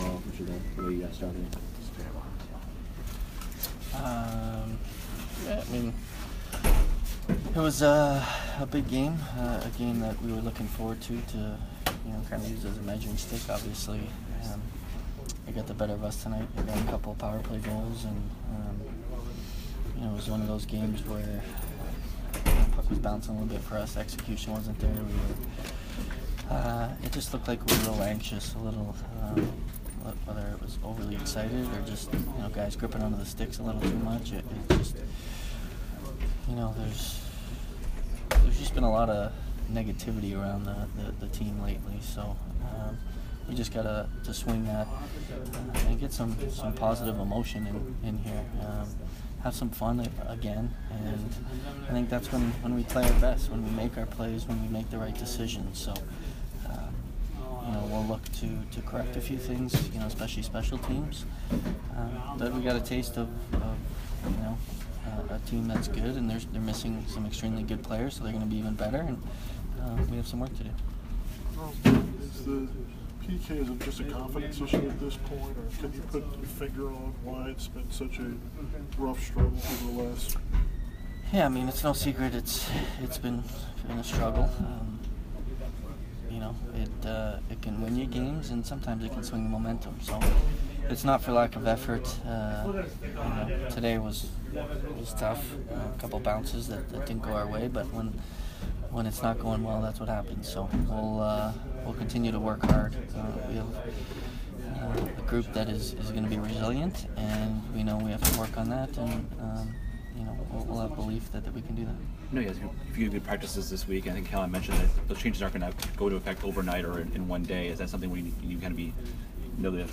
Steven Stamkos post-game 3/17